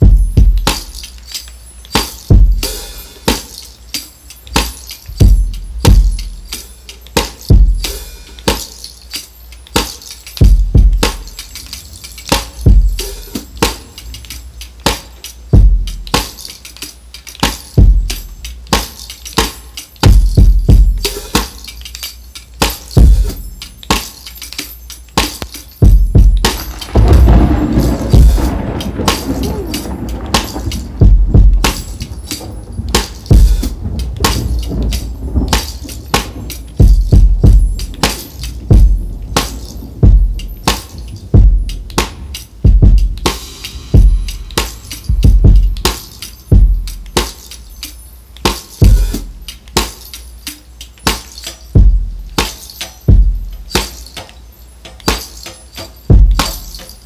Extended Thunder Drums.wav